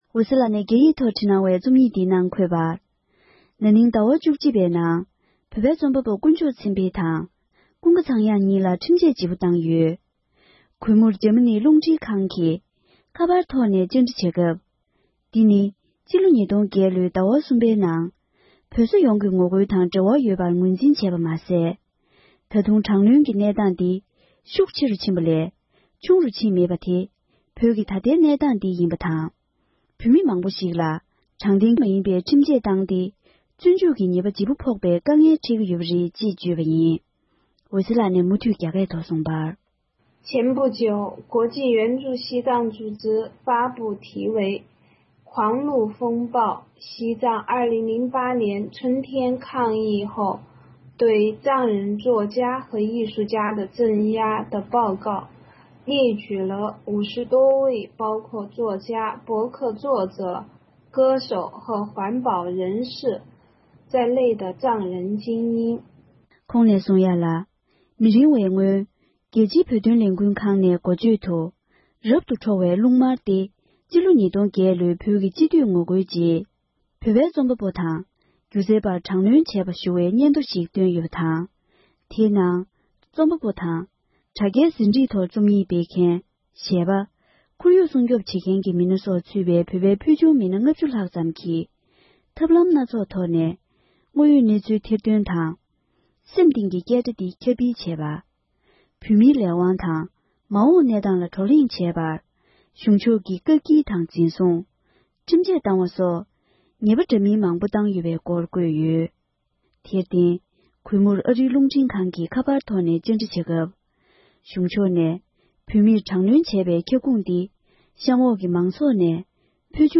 རྩོམ་པ་པོ་གྲགས་ཅན་འོད་ཟེར་ལགས་ཀྱིས་སྤེལ་བའི་དཔྱད་རྩོམ།
སྒྲ་ལྡན་གསར་འགྱུར།